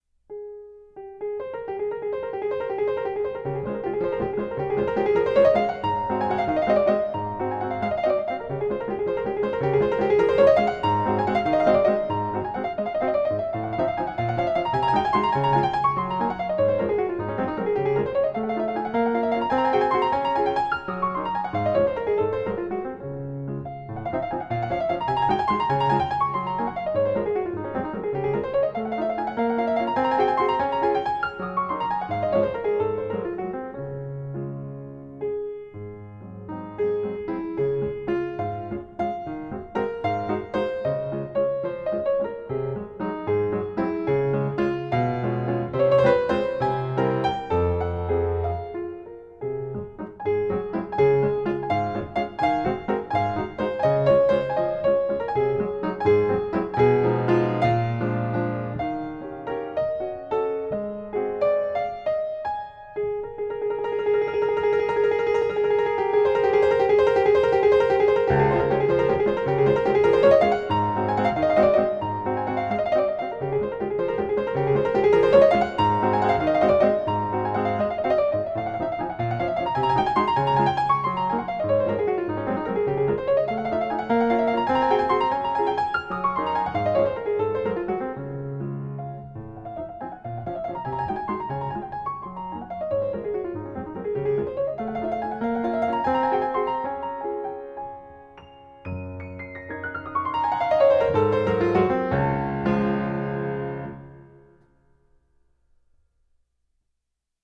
コロコロ上下感の音を駆け巡る、別名１分ワルツ。
ほぼ全体を一気に駆け巡る軽快さ。
ベース音ような左手の１拍目と、軽快な右手を特に意識することで、コロコロとした愛らしさが際立ちます。